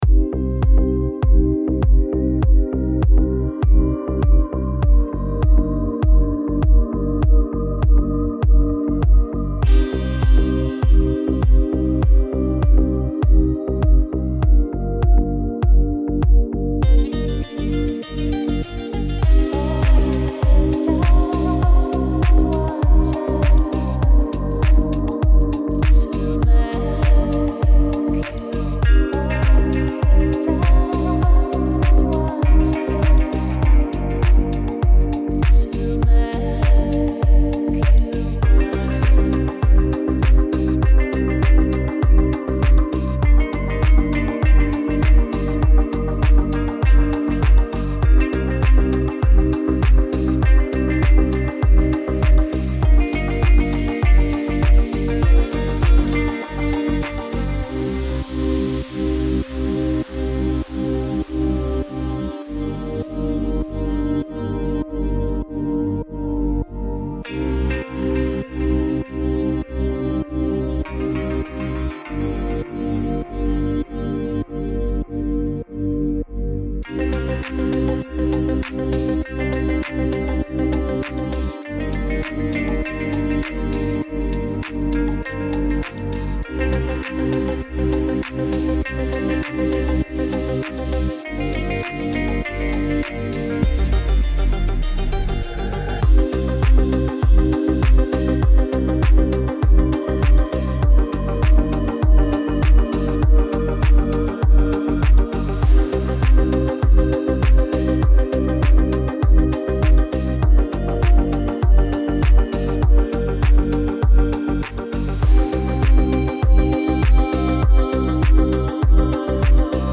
سبک دیپ هاوس , ریتمیک آرام , موسیقی بی کلام